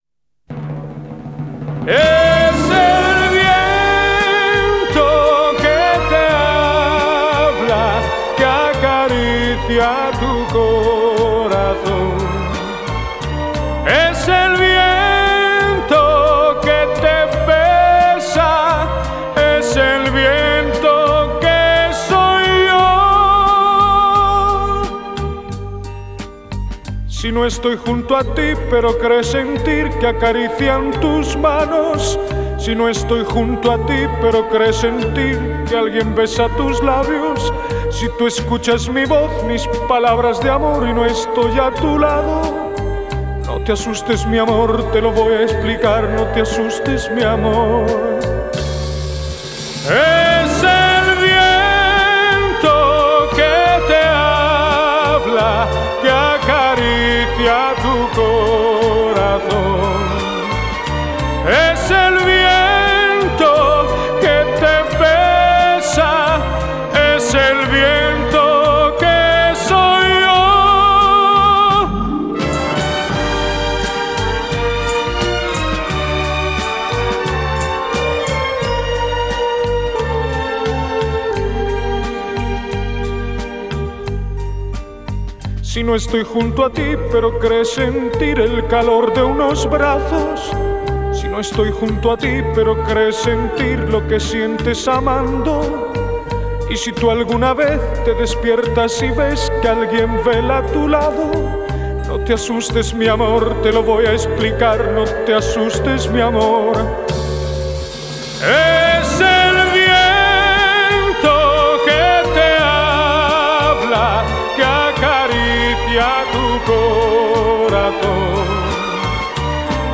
Cancion Española